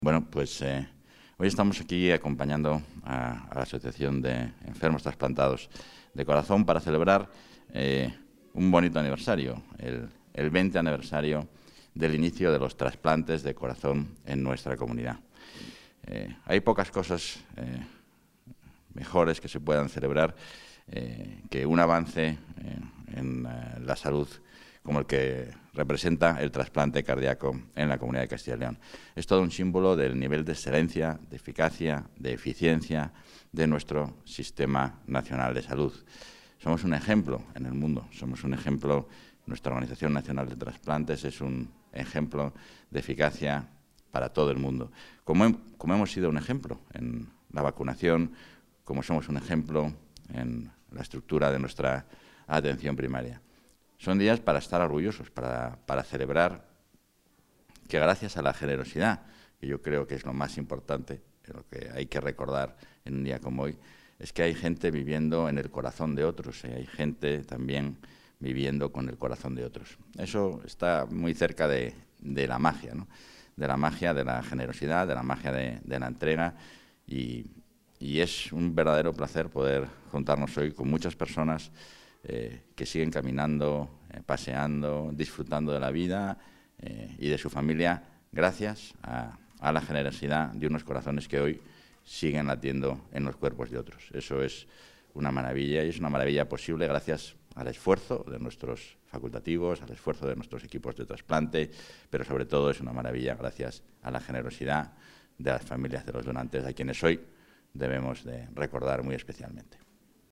Declaraciones del vicepresidente de la Junta.